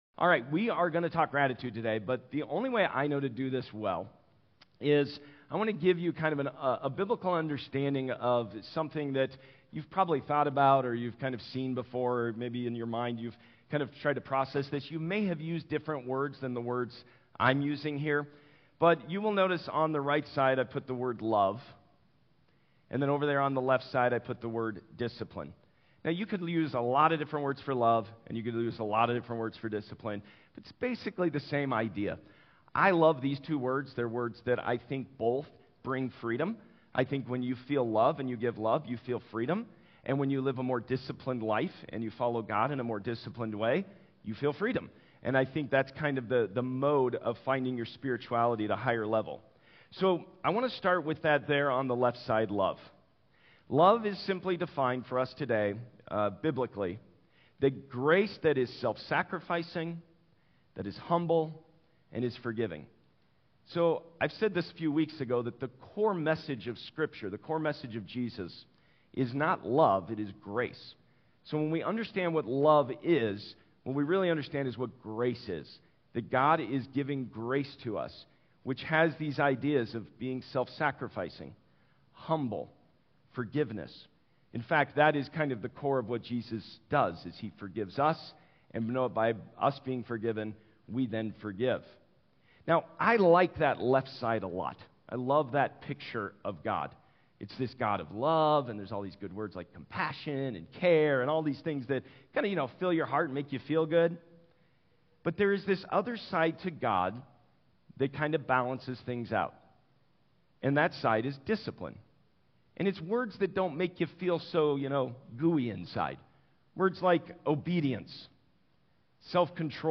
Watch or listen to recent Sunday messages and series from The Journey Church in Westminster, CO. New sermons posted weekly with video and notes.